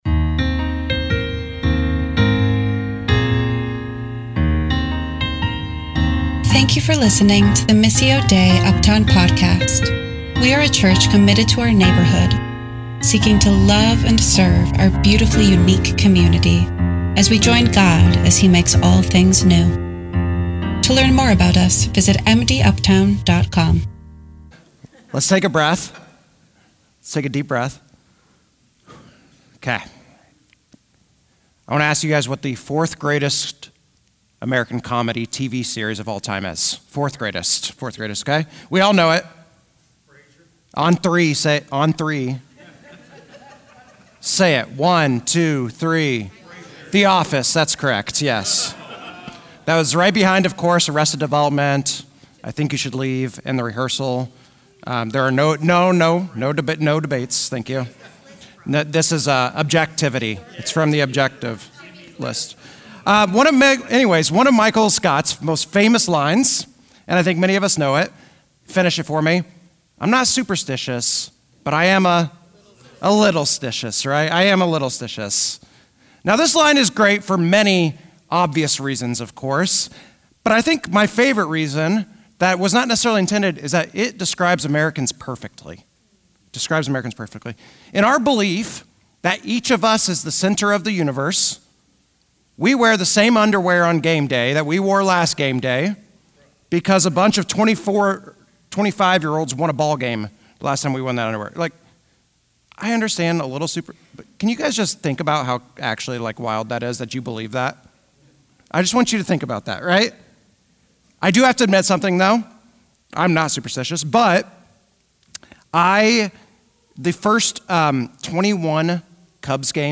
The Sermon – Ask.